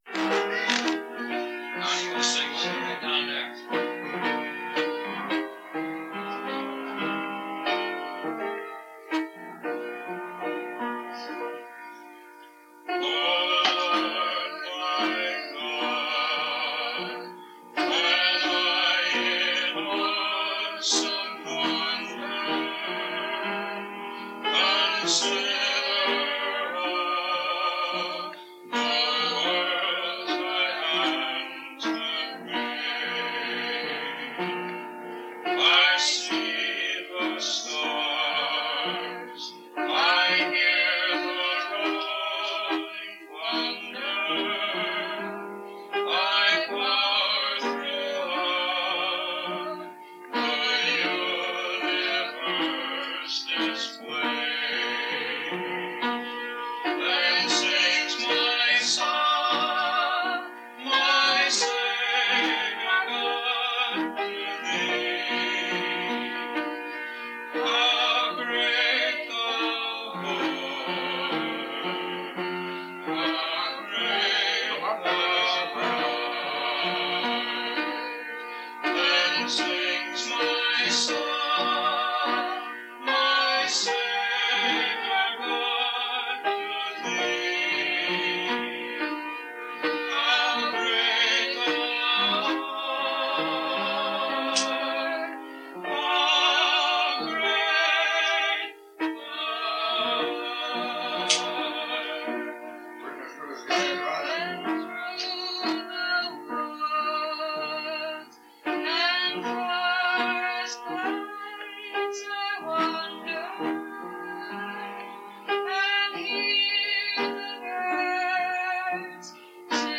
This version has been run through Audacity for some basic editing and noise reduction.